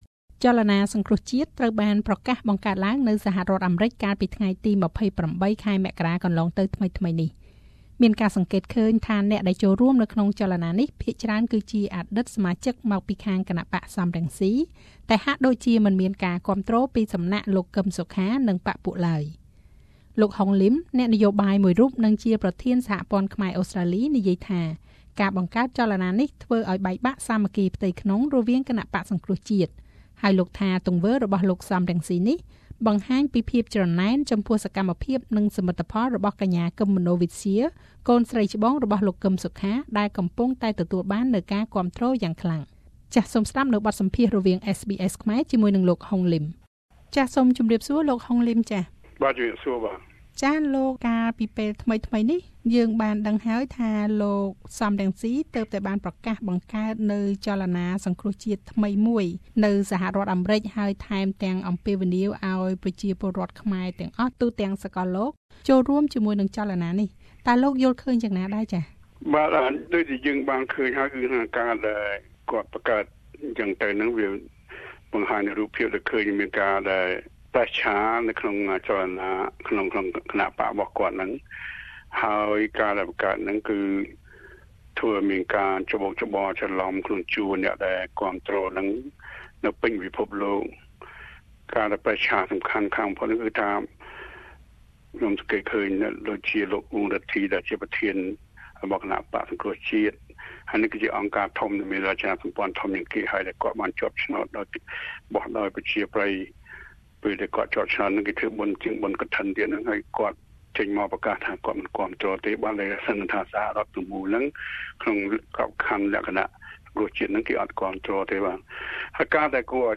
(សំឡេង លោក សម រង្ស៊ី)
(សំឡេង លោក ហ៊ុន សែន)